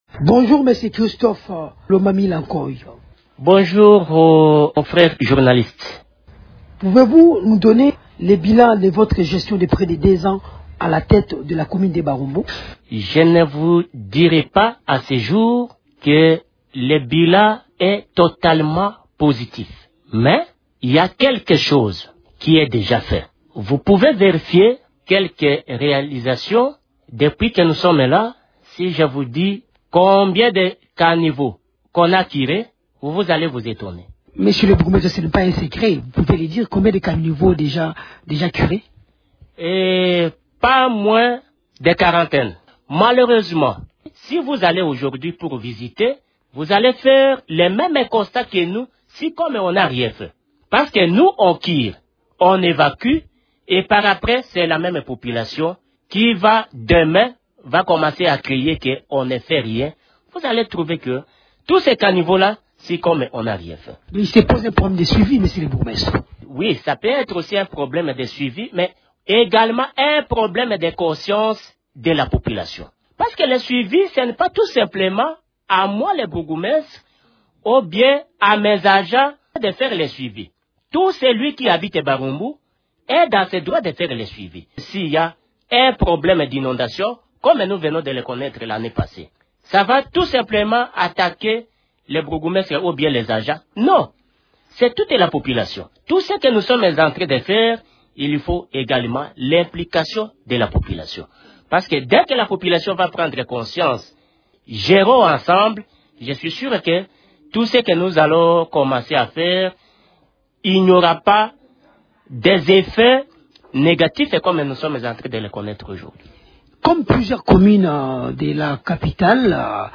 Invité de Radio Okapi, Christophe Lomami demande à sa population de s’adonner aux travaux de salubrité pour rendre son environnement sain afin de se préserver de maladies des mains sales.